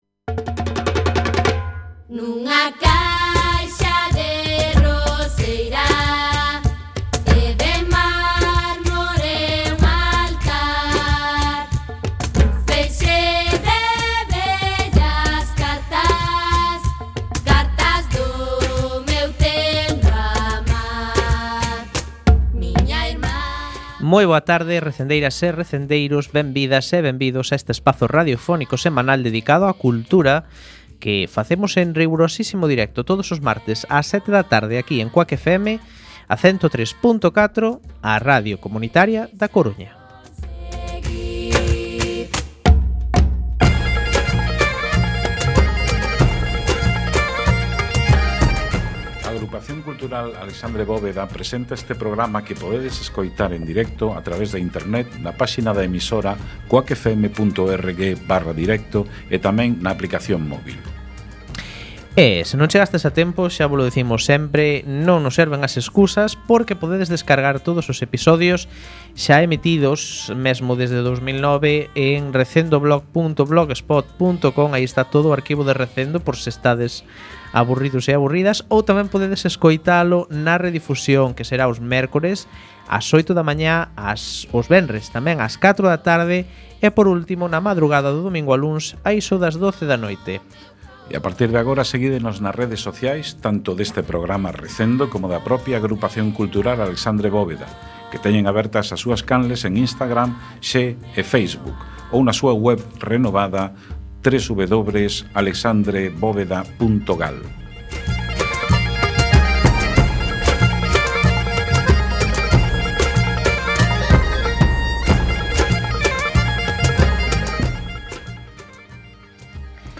Programa número 502, no que entrevistamos